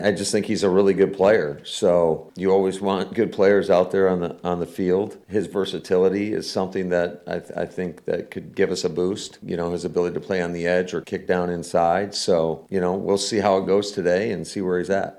(LEARFIELD) – Green Bay Packers coach Matt LaFleur covered a lot of topics when he spoke with the media on Thursday.
LaFleur said that he brings a lot of value if he can return.